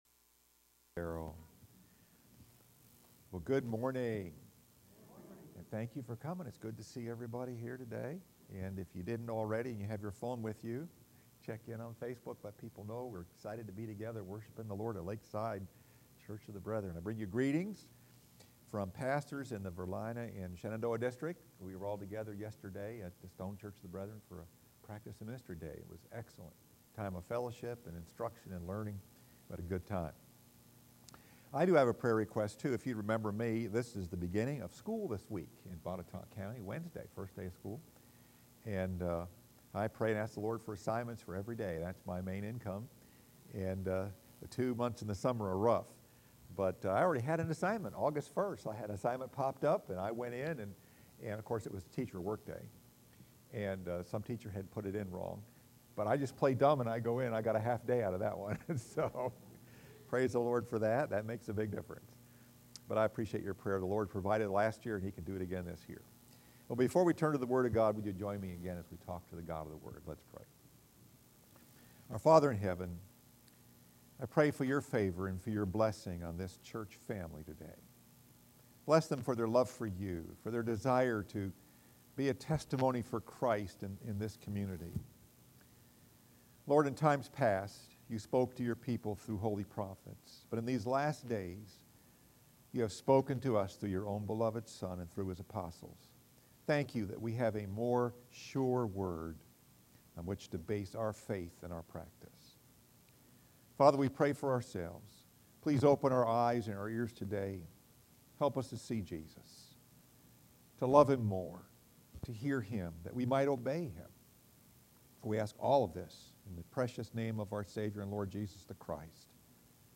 Message: “What Time is It?” Scripture: Acts 1:1-14